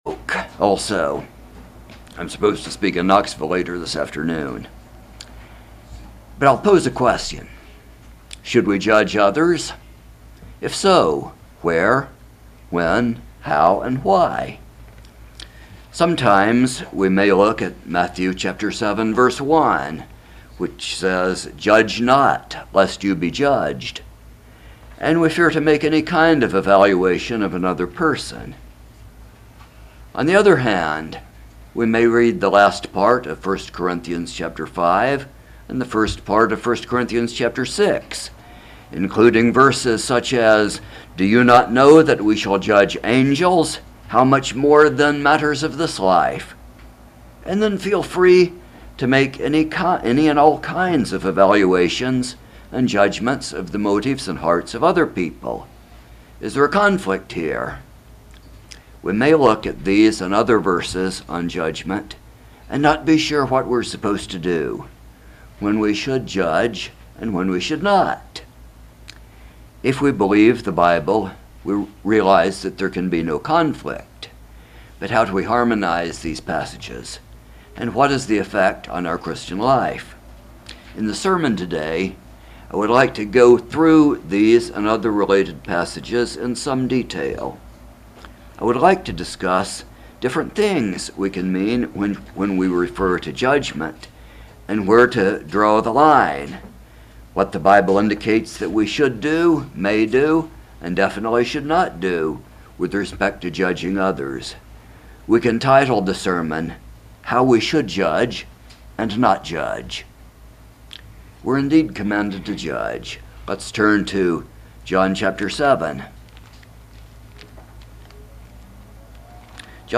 Do these verses contradict one another? This sermon examines where, when, how, and why Christians should judge or not judge at all.